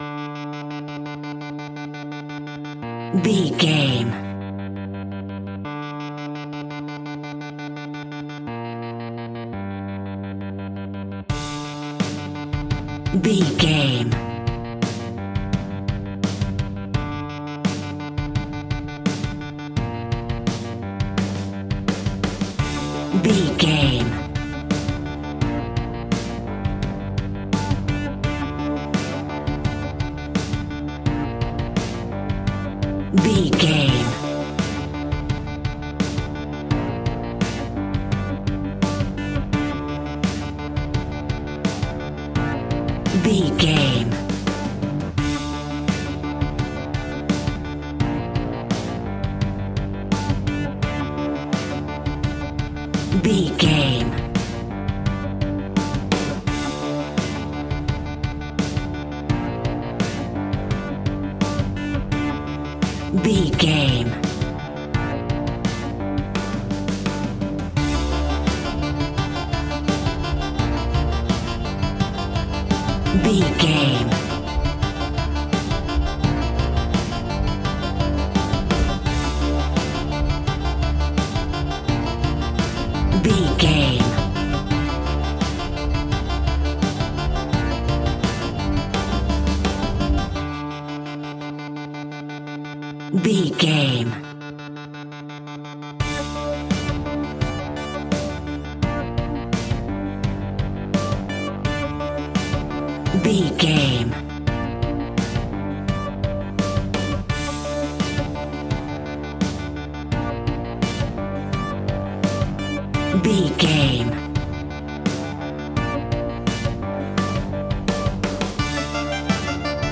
Big and huge haunting electronic tension horror music track.
Ionian/Major
piano
synthesiser